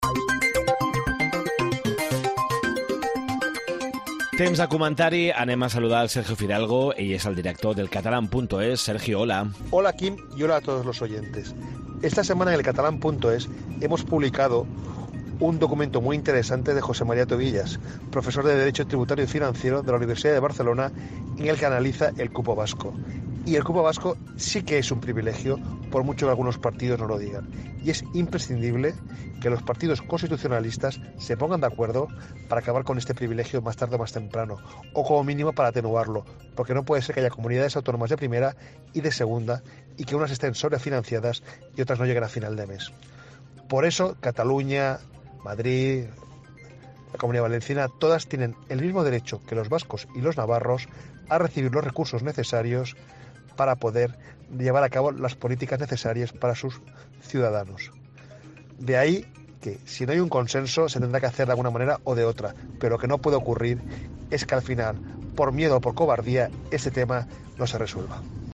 Comentari